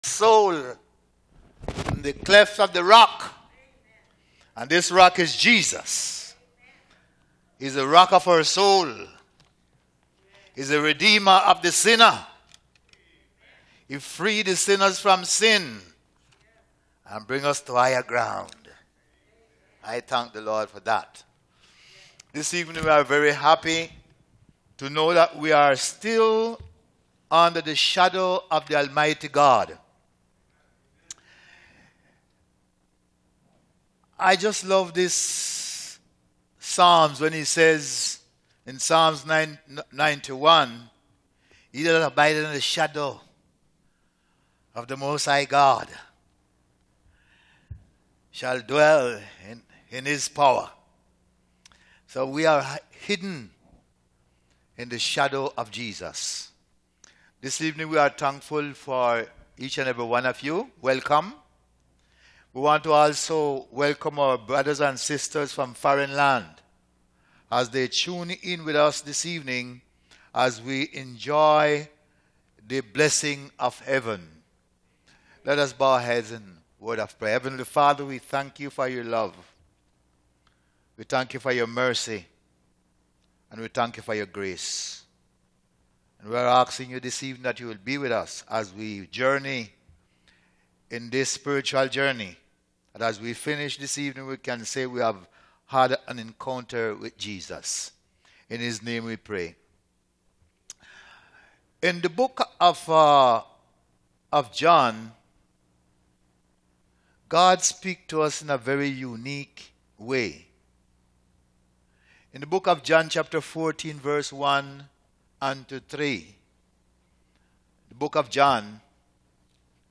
Seventh-day Adventist Church